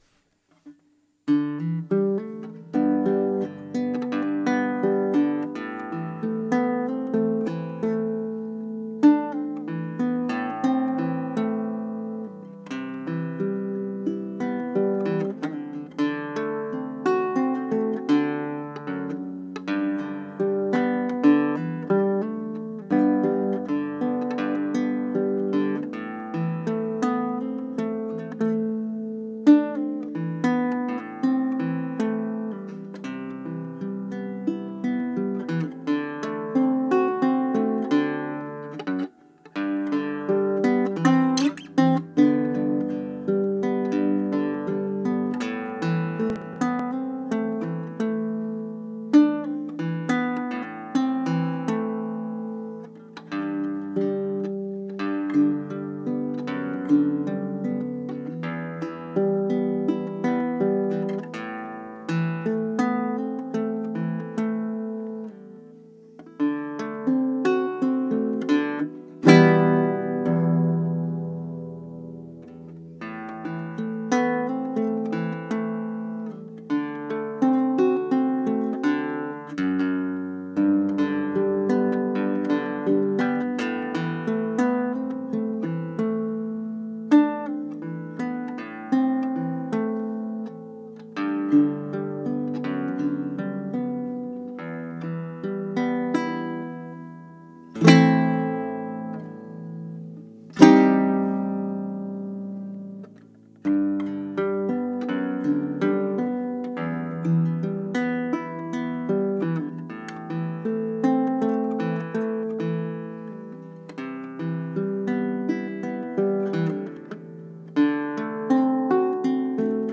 Baritone Harp Ukulele
Combining the warmth of a baritone ukulele with the extended tonal range of harp-like bass strings, this unique instrument delivers an unparalleled sound experience — rich, resonant, and ethereal.
• Standard 4-string baritone tuning (D–G–B–E) + 3–5 harp strings (variable tuning)
Immerse yourself in the layered harmonics of the Baritone Harp Ukulele.
Harp-Uke-Sound-Sample.mp3